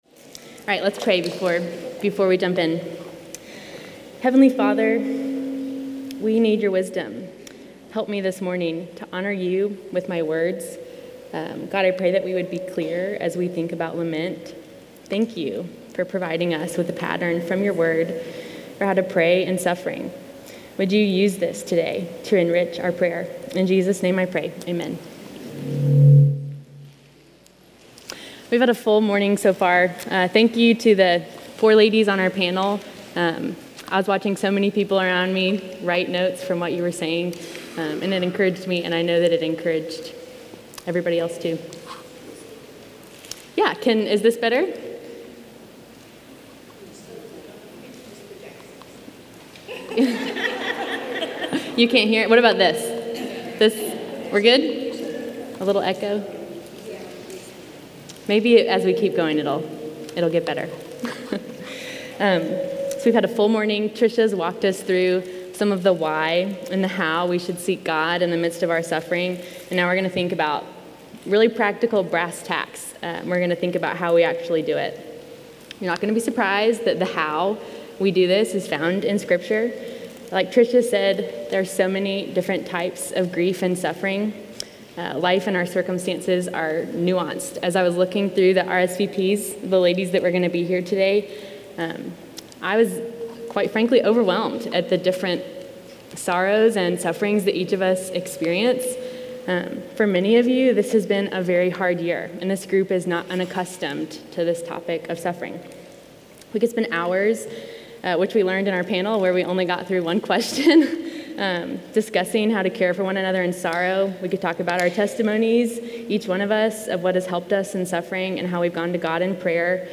Audio recorded at MVBC’s 2022 Women’s Counseling Conference.